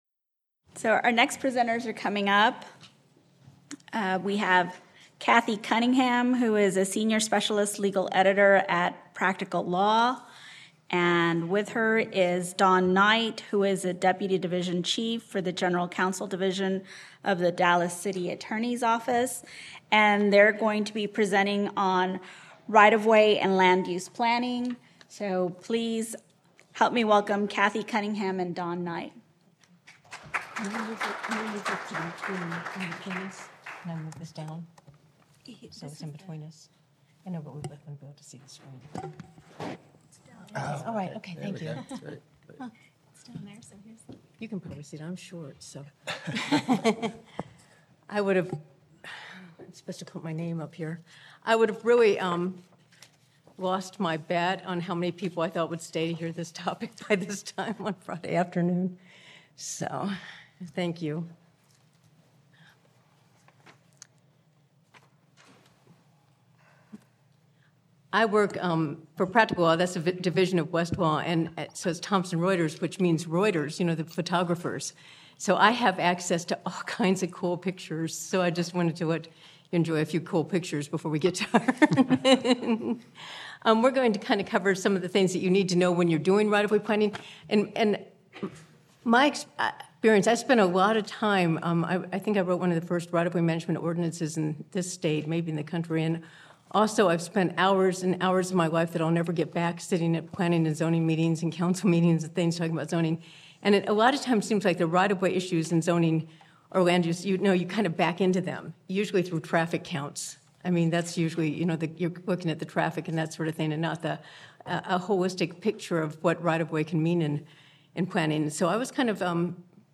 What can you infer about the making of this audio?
Originally presented: Apr 2024 Land Use Conference